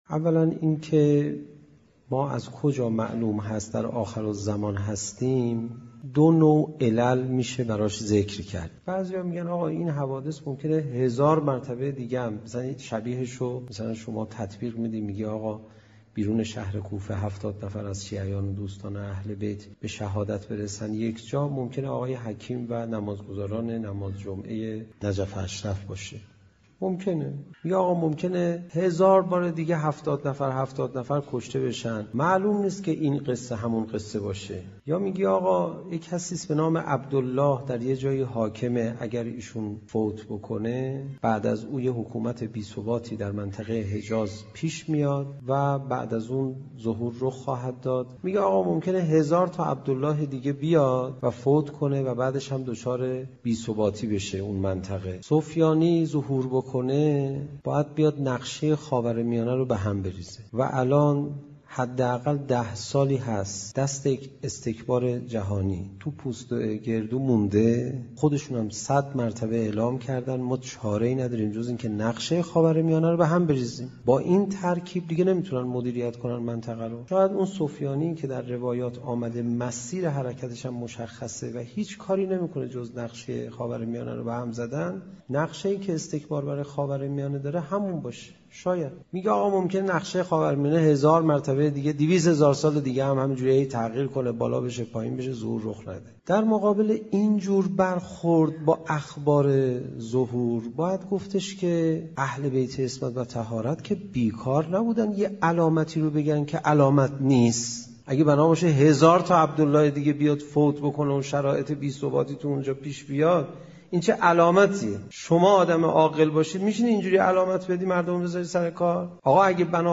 گزیده ای از سخنرانی